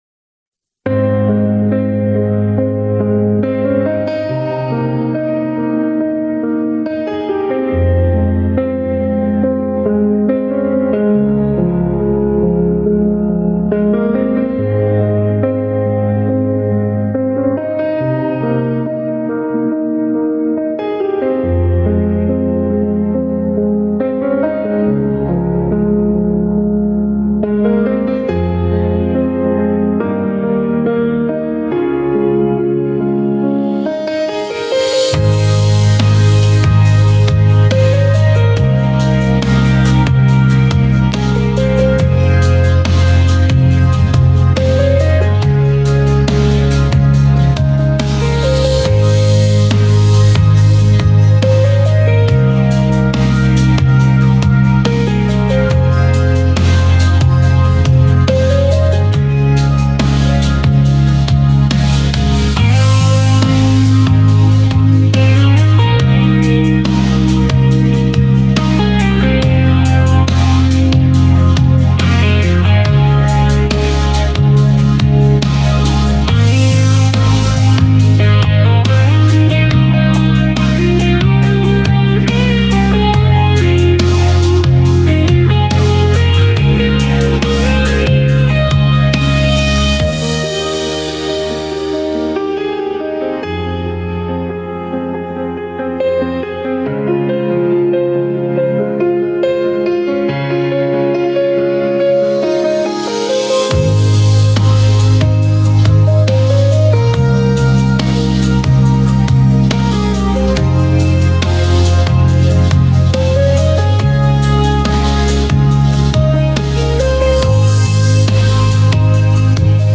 • Язык: Instrumental
Инструментальная пьеса